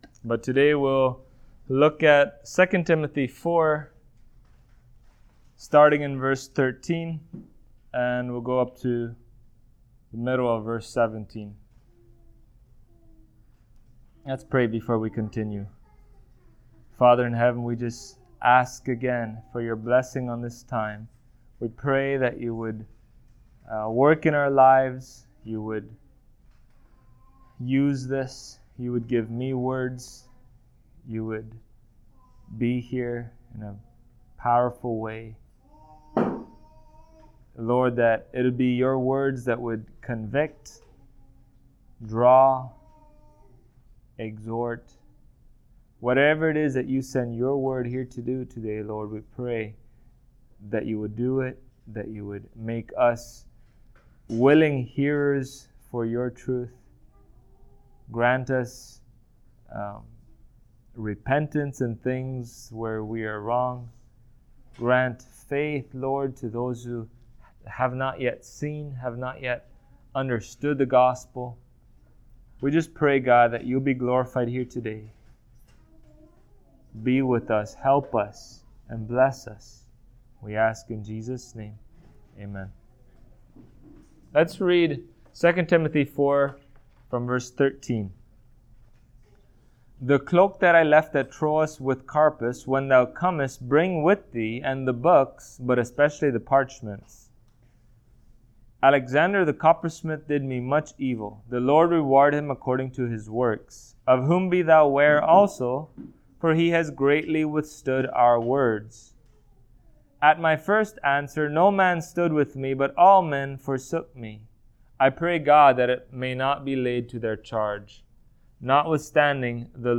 Passage: 2 Timothy 4:13-17 Service Type: Sunday Morning